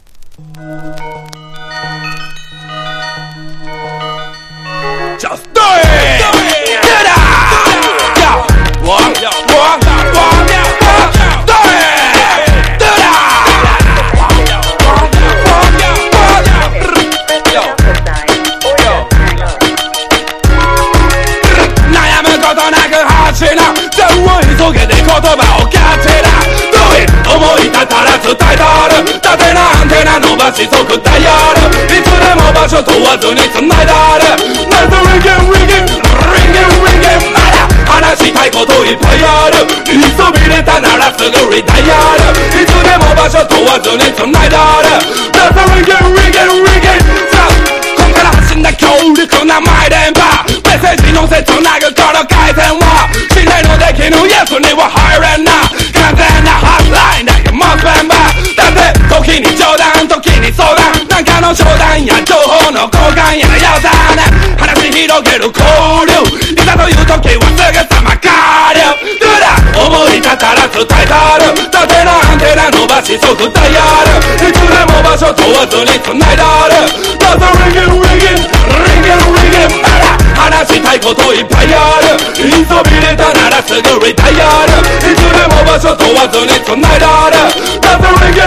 大阪を代表するダンスホール・レゲエ集団
DANCE HALL# REGGAE